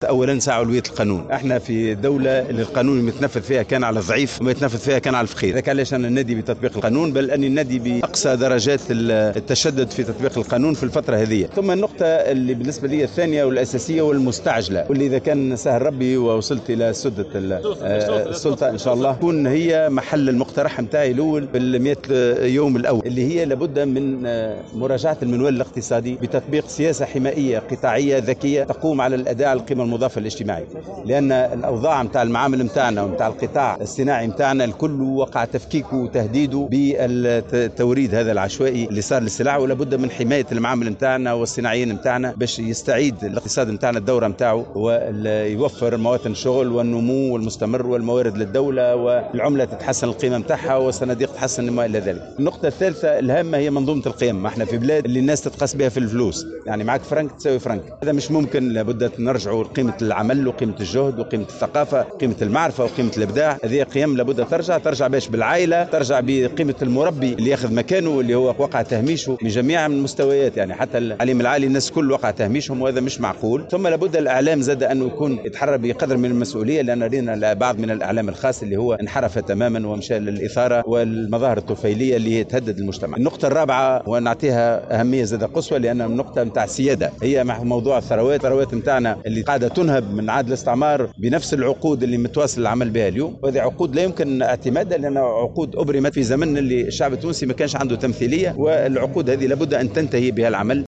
أكد المترشح للرئاسة لطفي المرايحي في تصريح للجوهرة "اف ام" أن علوية القانون على رأس أولوياته مؤكدا على ضرورة فرض أقصى درجات التشدد في تطبيق القانون في الفترة القادمة.